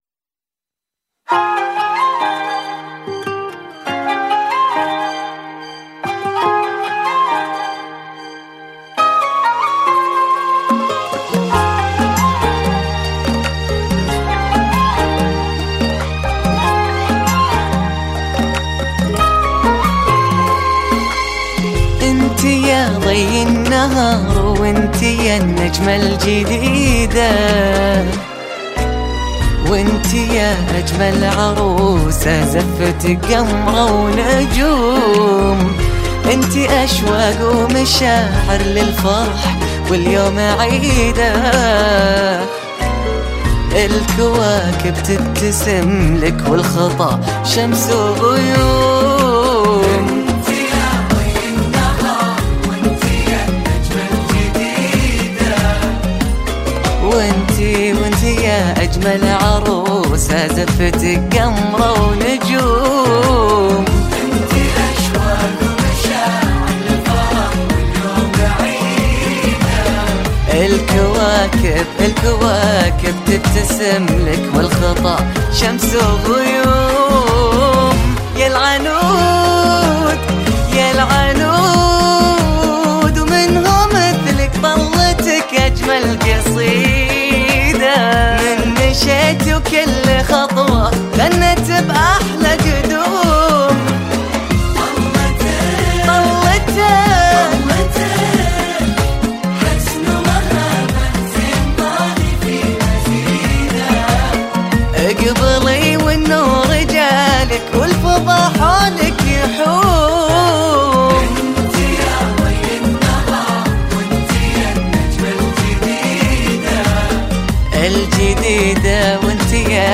زفات بدون موسيقي
زفة مسار